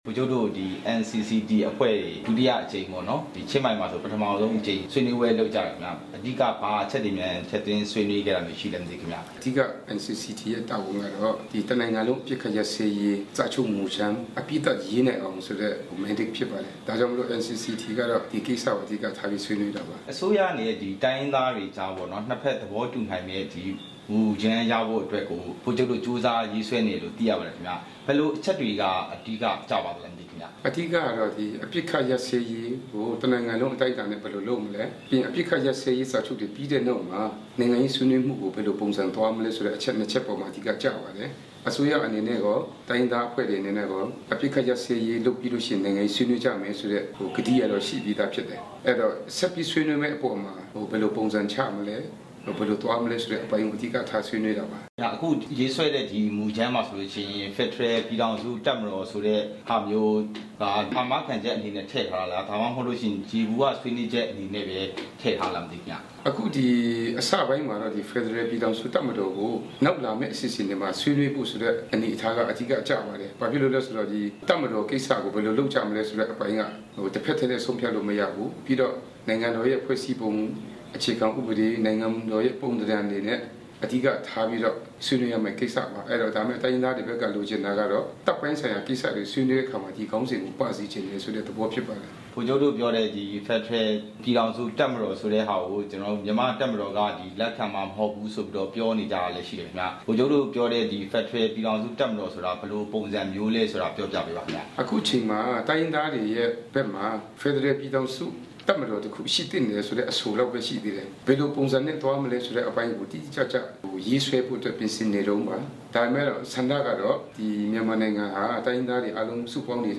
ဗိုလ်ချုပ်ဂွမ်မော်နဲ့ မေးမြန်းချက်